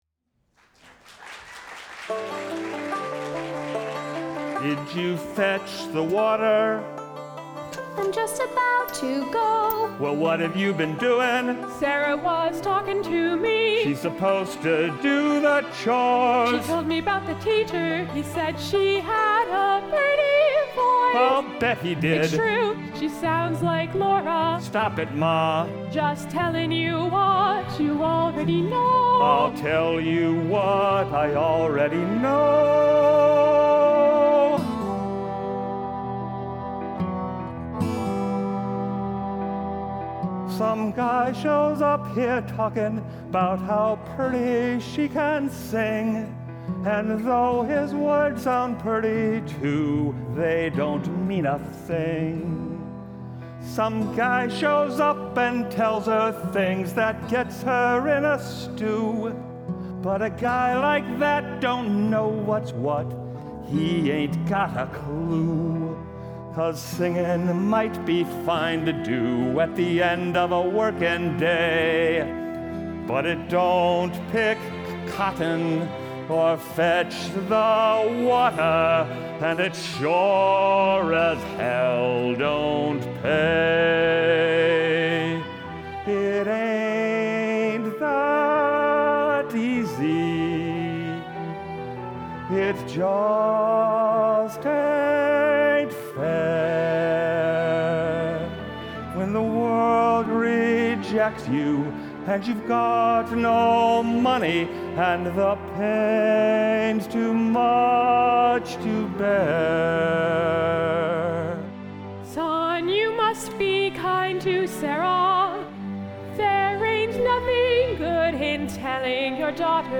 Listen to / Watch He Said That I Could Sing sung by Sarah and Agnes Listen to / Watch He Said That I Could Sing sung by Sarah and Agnes